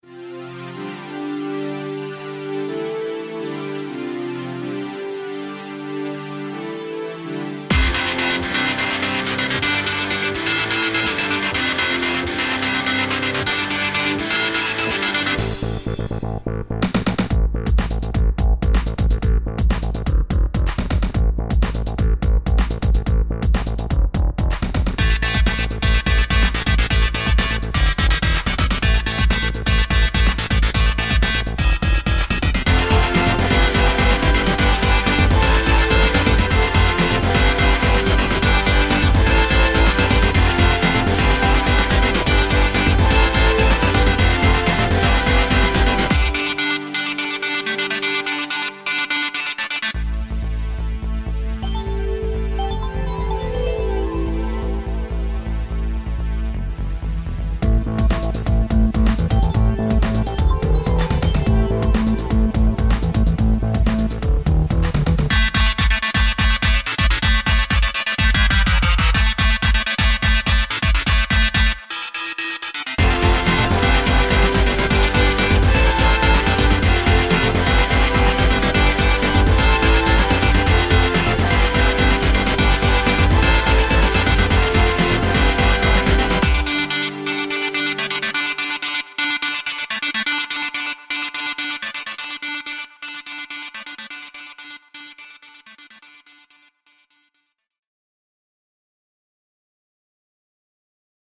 BPM125
Audio QualityPerfect (Low Quality)
TECJHNO